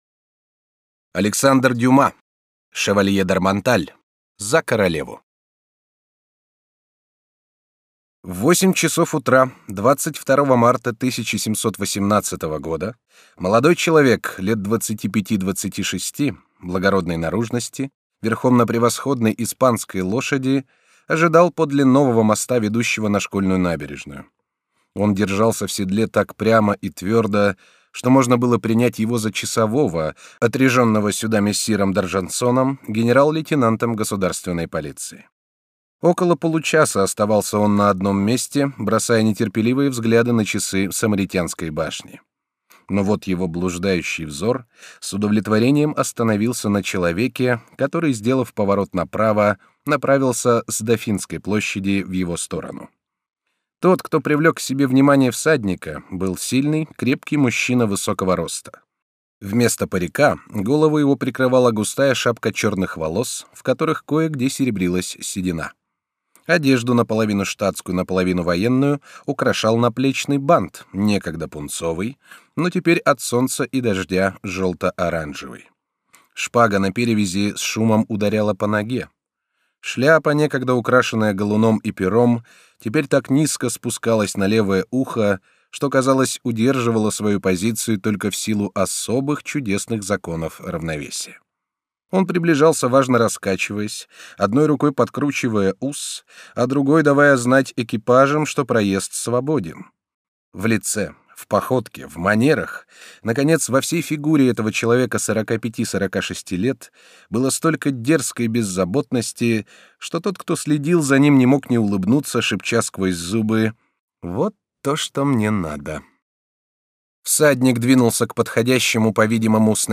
Аудиокнига Шевалье д’Арманталь (За королеву) | Библиотека аудиокниг